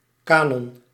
Ääntäminen
France: IPA: [ka.nɔ̃]